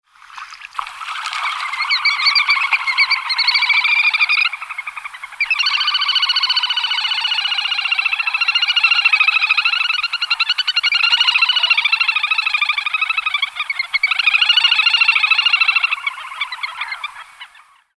Index of / stock ancien/6/09_le_carnaval/sons oiseaux/1jeux
grebe2.mp3